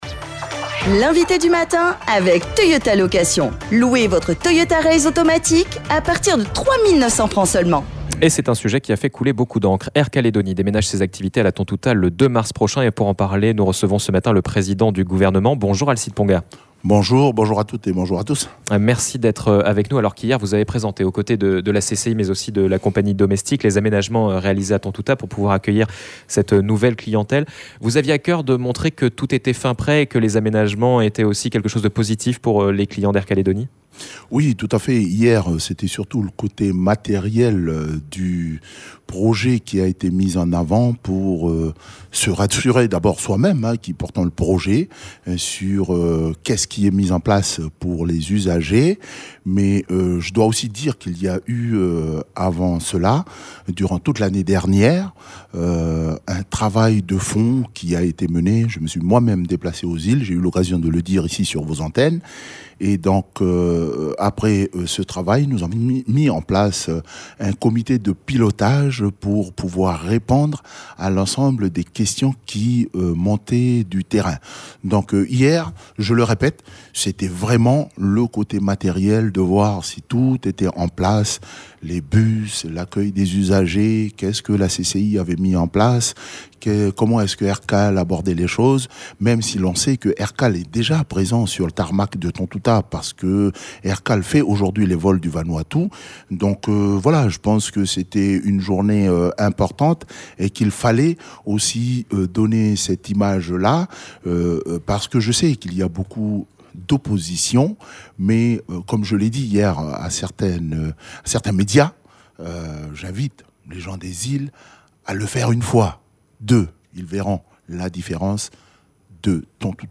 Une mutualisation qui vise à baisser les coûts pour la collectivité et retrouver la rentabilité. 179 000 passagers Aircalédonie devraient passer par la Tontouta en 2026. Nous en avons parlé avec le président du Gouvernement Alcide Ponga.